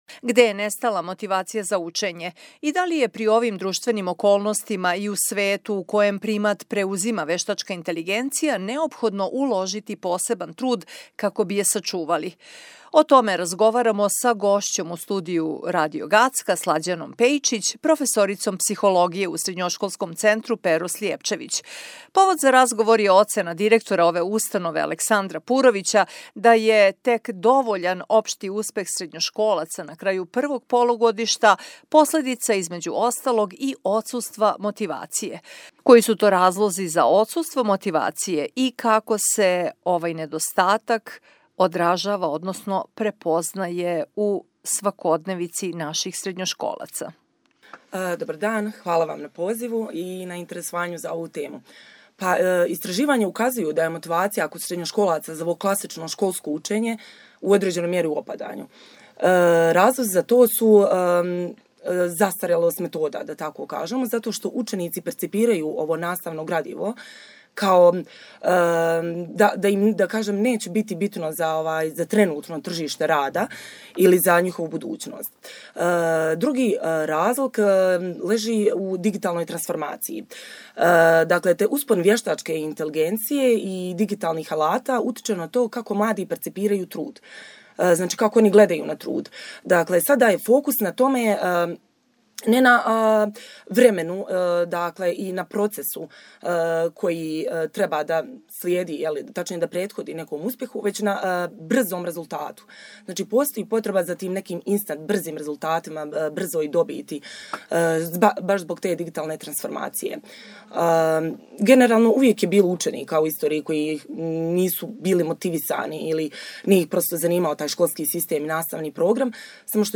O tome smo razgovarali sa gošćom Radio Gacka